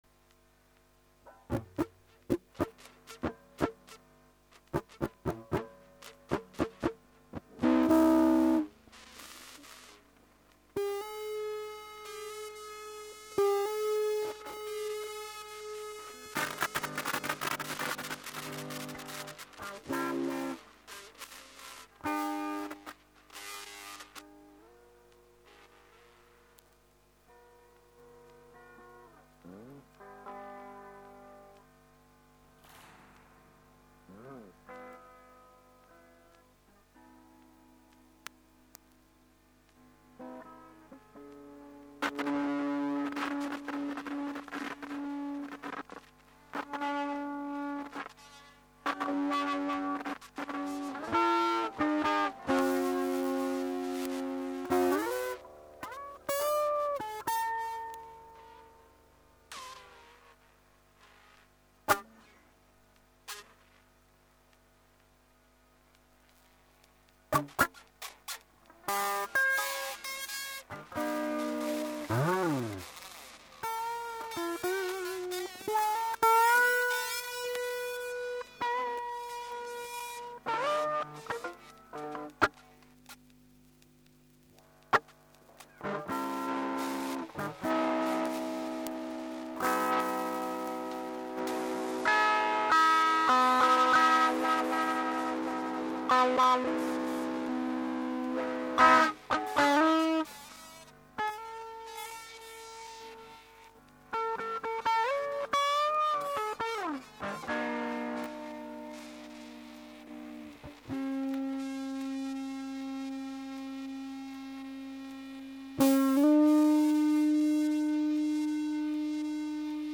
スプリングリバーブの雑音、
ポットガリガリンどぅすっかとぃぅ事であり、耐久性抜群御値段
potII.mp3